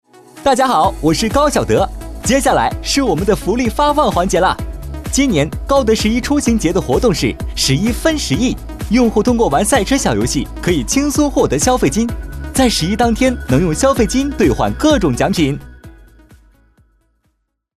样音试听 - 红樱桃配音-真咖配音-500+真人配音老师 | 宣传片汇报纪录动画英文粤语配音首选平台
红樱桃配音，真咖配音官网—专业真人配音服务商！